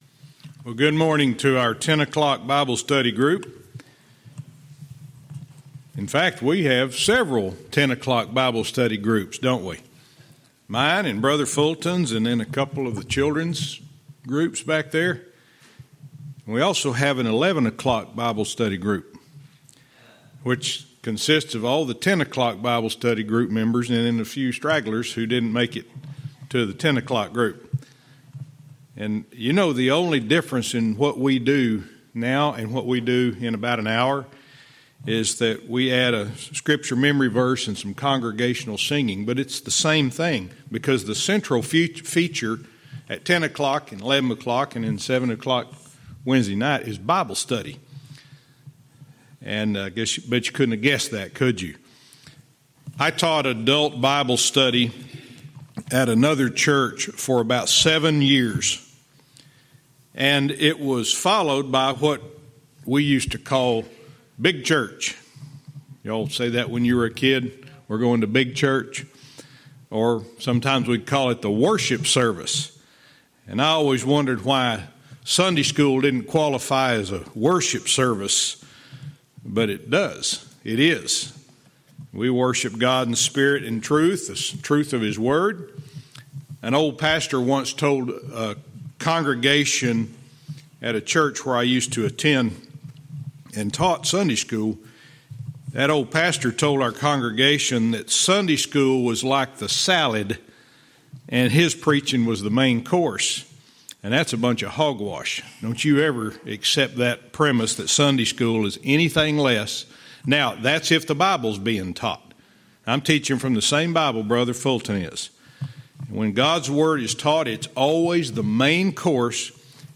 Verse by verse teaching - 2 Kings 19:4(cont)